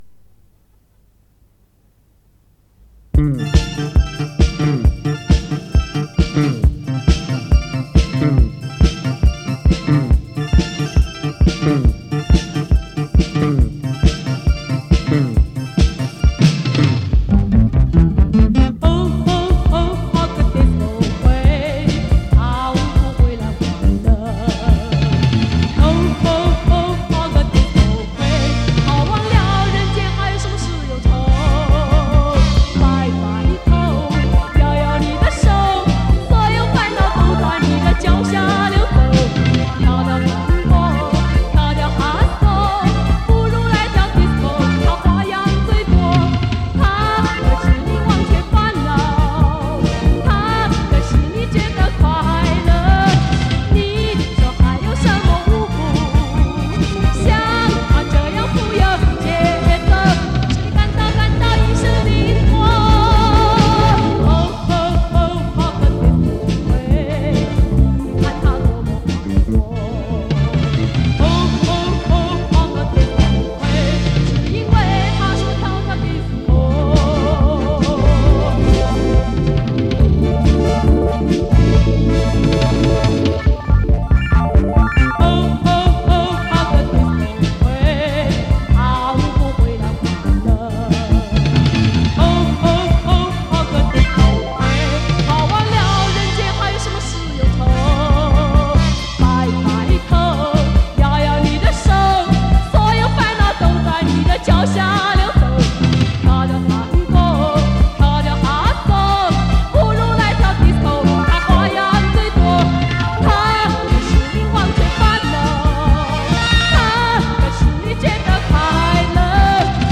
娴熟的演唱，富有韵味的吐字，以及热情奔放的风格，会给你的生活带来愉快地享受。